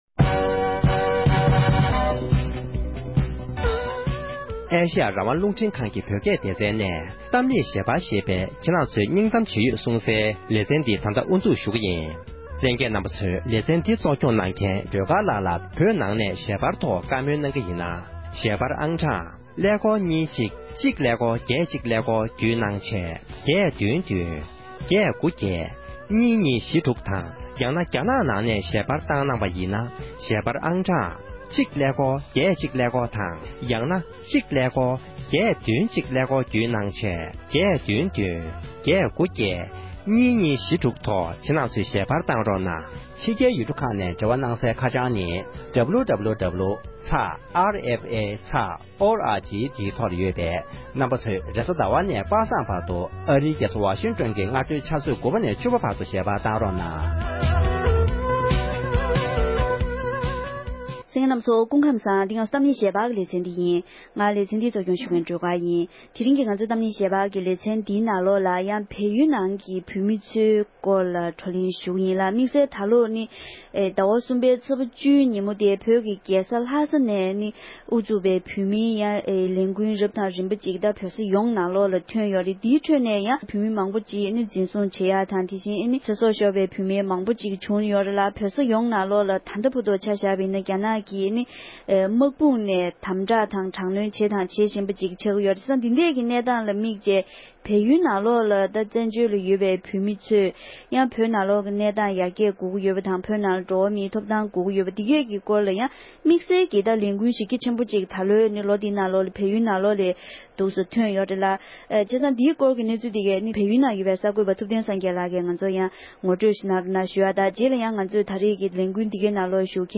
འབྲེལ་ཡོད་མི་སྣར་བགྲོ་གླེང་ཞུས་པ་ཞིག་གསན་རོགས་གནང༌༎